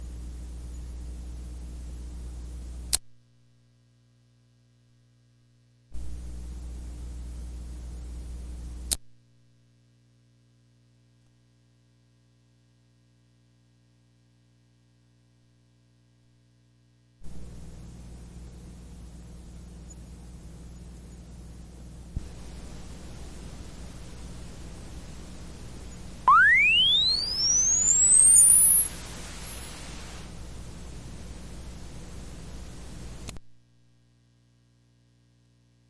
盒式磁带 " 盒式磁带嘶嘶声和接地哼声开始，停止点击+上升音
描述：盒式磁带嘶嘶声和地面嗡嗡声开始，停止点击+上升音调.flac
Tag: 地面 单击 卡带 嘶嘶声 上升 磁带 启动 停止 嗡嗡声